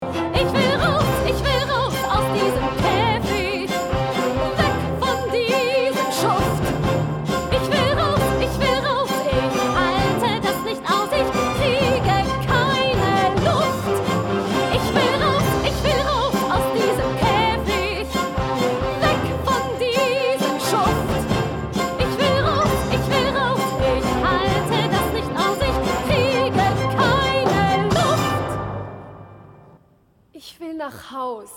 Piano & Vocal Score
Piano + Vocals
Kinderoper